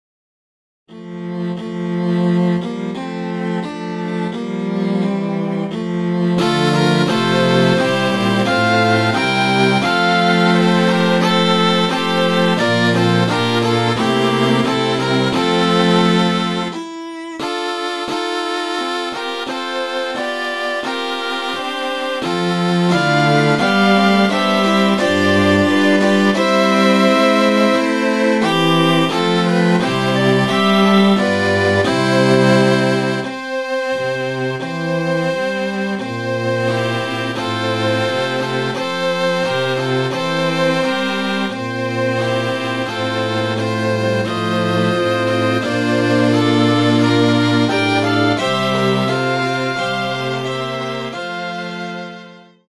Ohne Gesangstext, elektronisch vertonte Konzeptidee.
Seite 22 Erwachender Tag Morgenlied Op. 69 Nr.1 Chorwerk von Josef Rheinberger: Neu- Instrumentierung f�r Klarinette und Streichquartett.